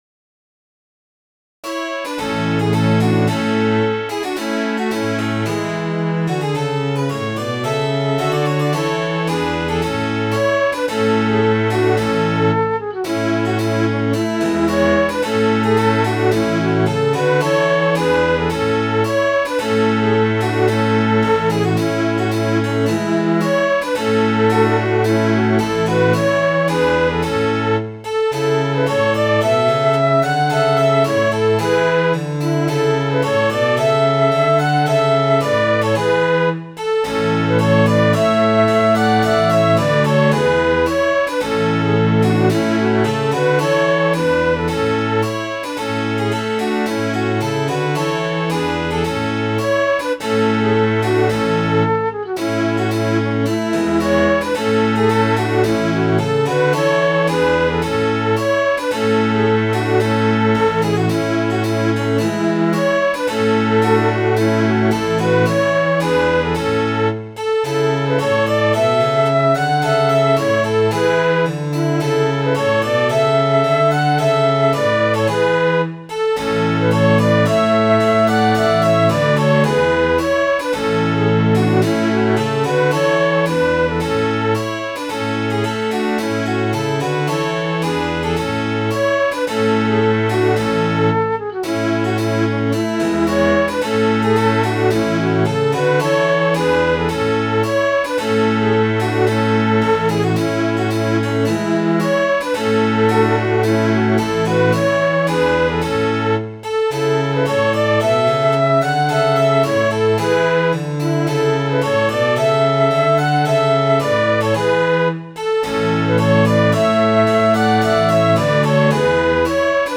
ganeawa.mid.ogg